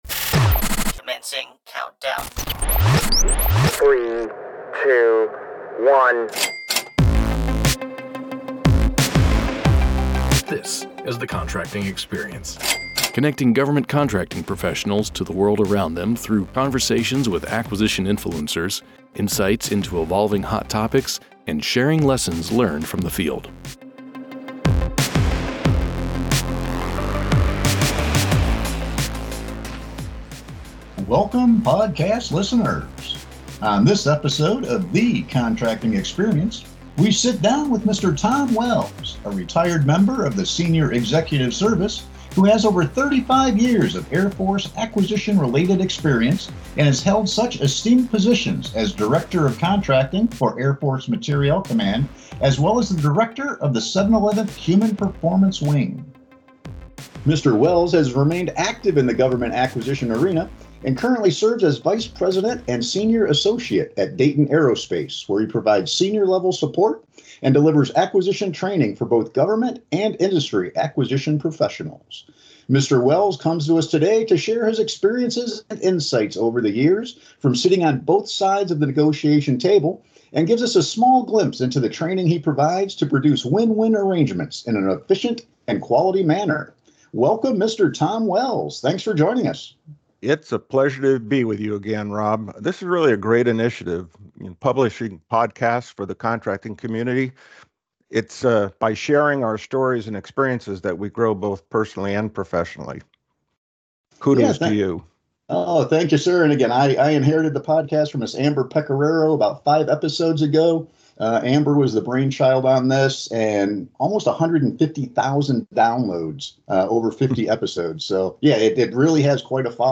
Tune in for an engaging and insightful conversation.